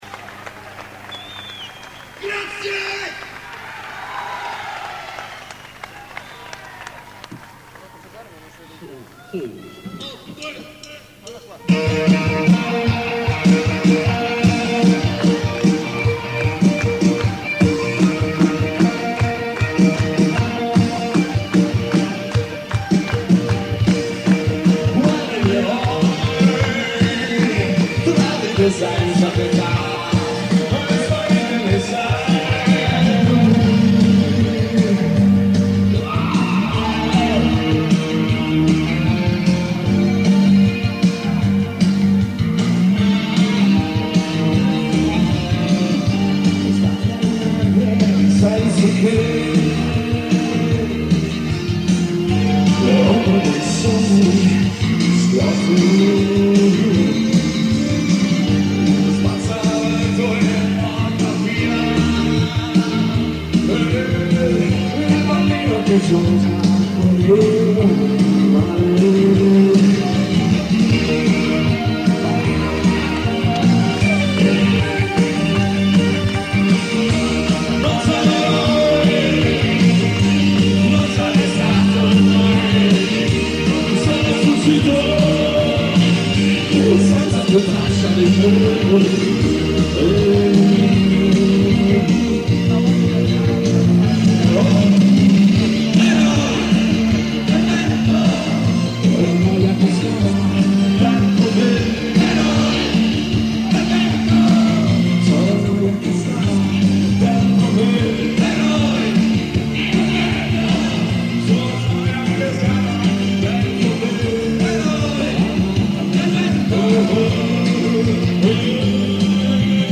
• Teatro Comunale di Alessandria
frammenti audio del concerto